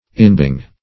Inbeing \In"be`ing\, n. Inherence; inherent existence.